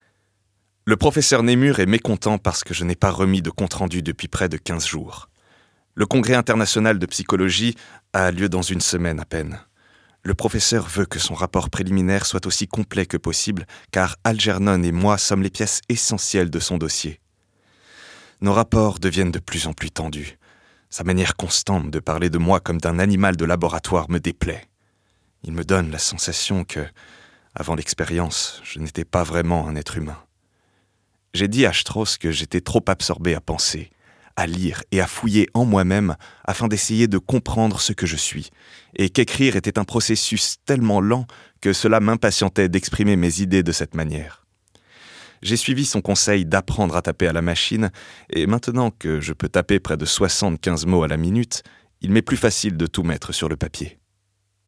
texte lu
20 - 45 ans - Baryton Ténor